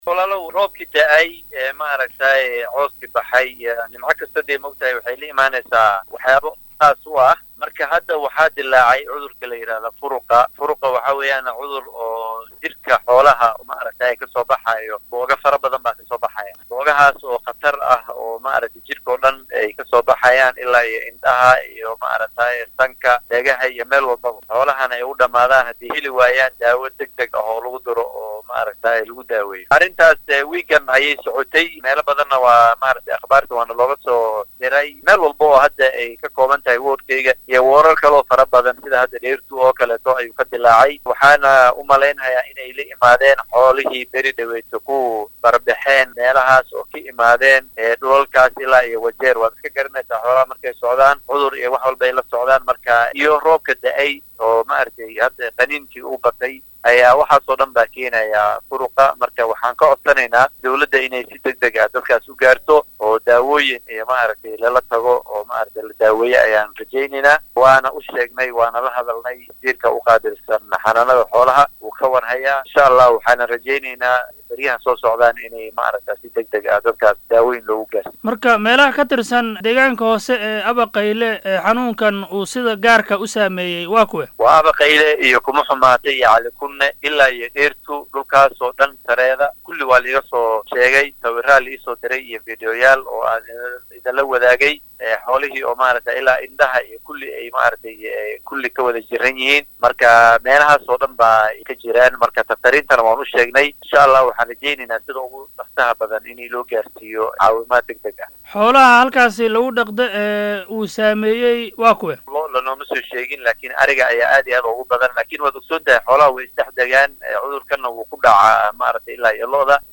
Wareysi-Wakiilka-Abaqeyle.mp3